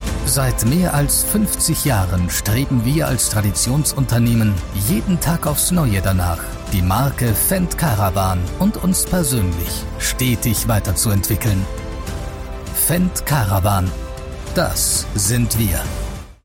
Naturelle, Polyvalente, Cool, Fiable, Corporative
Corporate